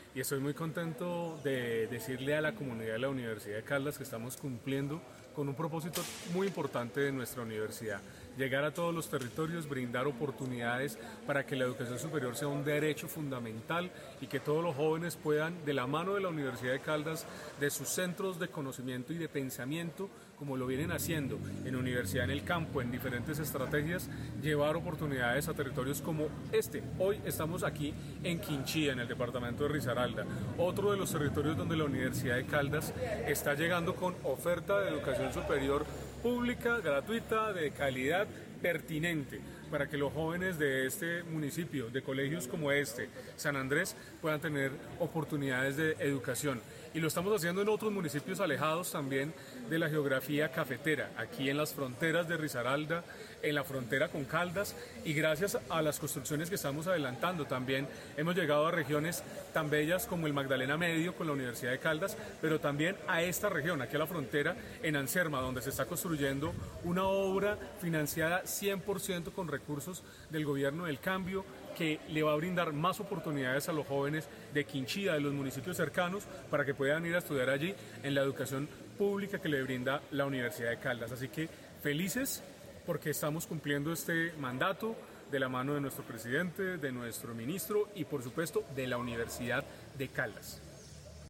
Durante la jornada, el viceministro de Educación Superior, Marino Patiño, destacó el alcance territorial de la estrategia y el papel de la Universidad de Caldas en la ampliación de oportunidades educativas, “Hoy estamos aquí en Quinchía, en el departamento de Risaralda, uno de los territorios donde la Universidad de Caldas está llegando con oferta de educación superior gratuita, y también estamos en otros municipios alejados de la geografía como el Magdalena Medio, ampliando la presencia de la universidad pública en territorios históricamente apartados.
-Audio Viceministro de Educación, Ricardo Moreno Patiño.